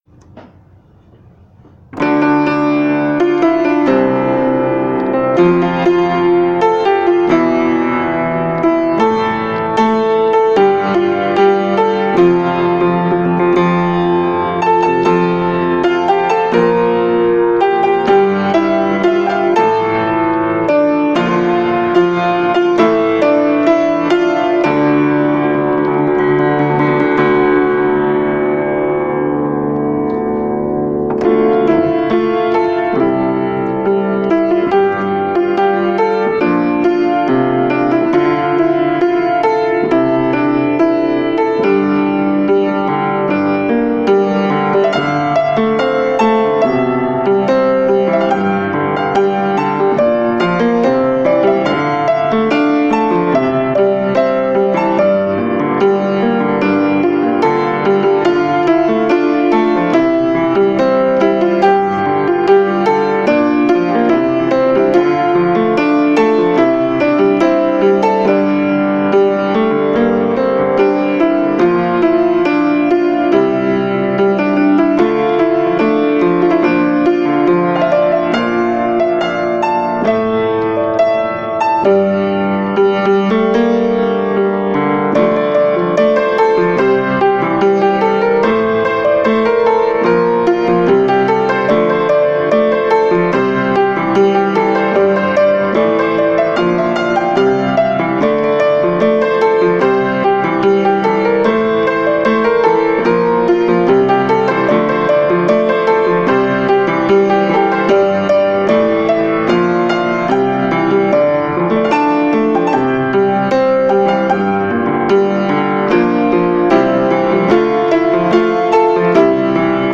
הנגינה ממש מדויקת!!!